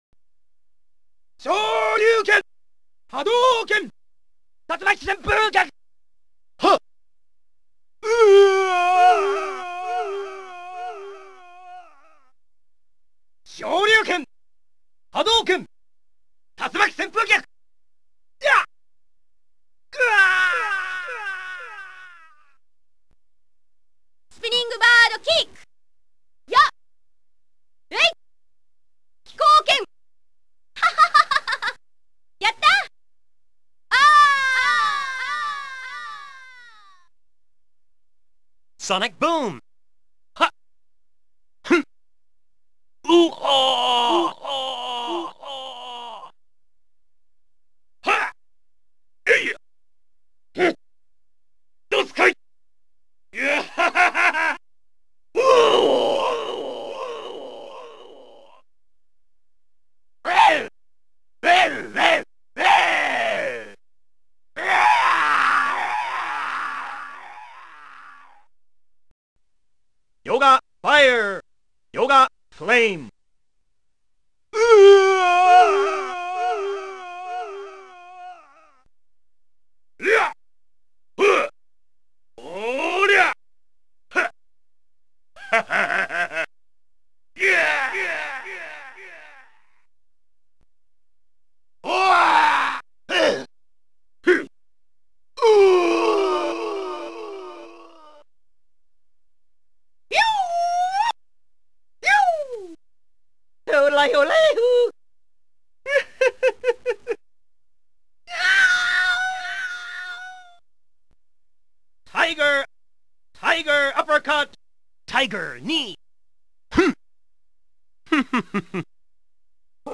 STREET FIGHTER VOICES.mp3